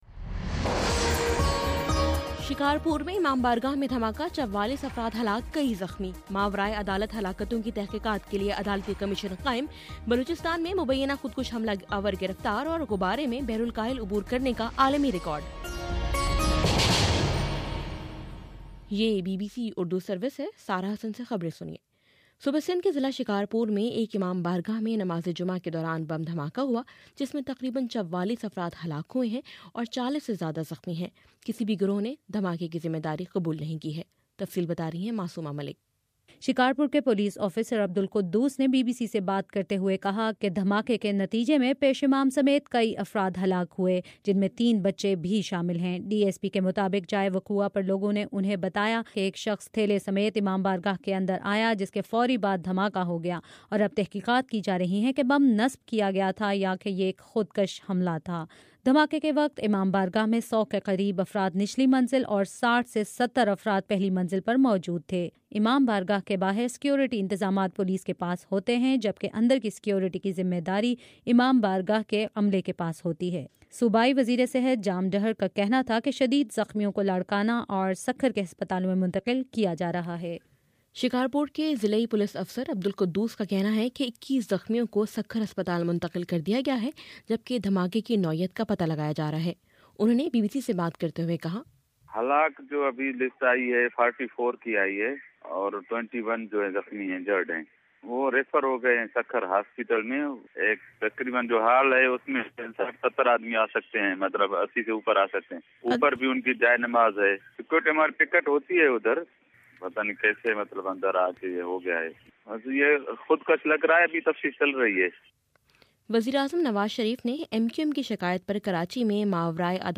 جنوری 30: شام چھ بجے کا نیوز بُلیٹن